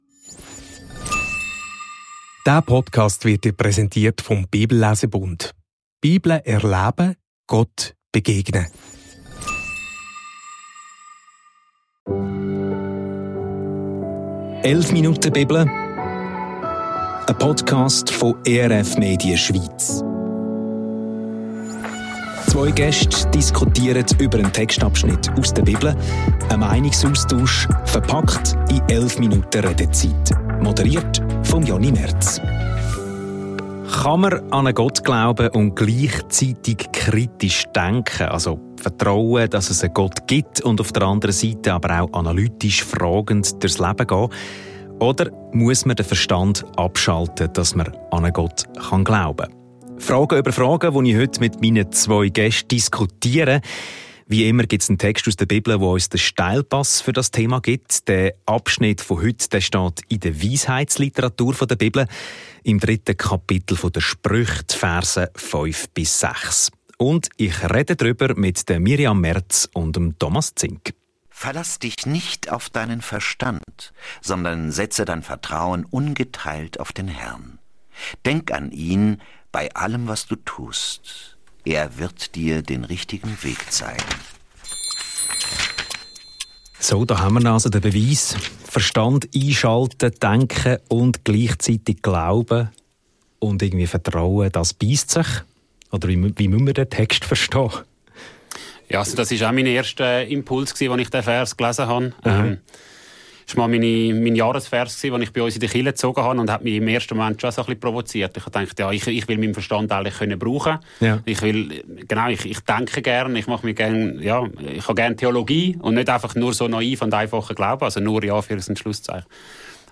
Glauben und Denken schliessen sich nicht aus – Sprüche 3,5–6 ~ 11 Minuten Bibel – ein Meinungsaustausch Podcast